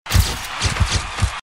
punch3_2.aac